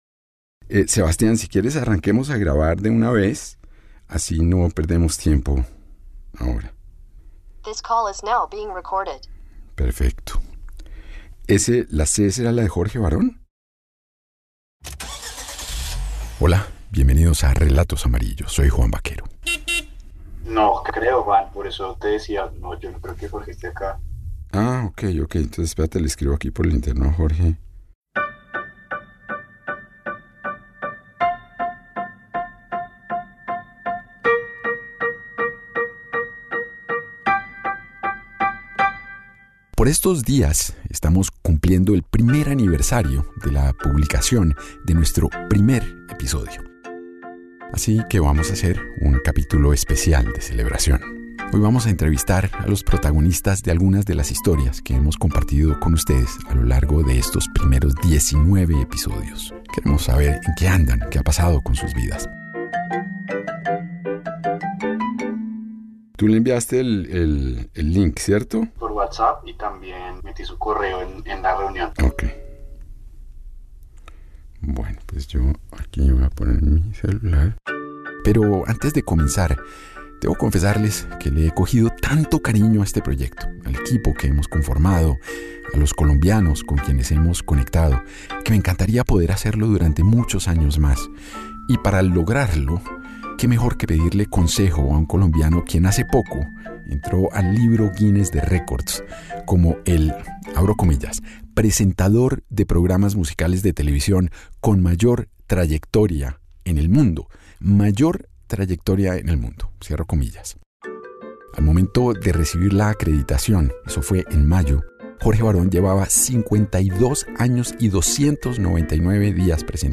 Un episodio fuera de lo común lleno de emociones, risas, anécdotas y el ‘detrás de cámaras’ de la canción creada para celebrar el cumpleaños del pódcast original de RTVCPlay.